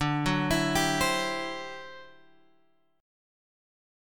D11 chord {10 9 x 9 8 8} chord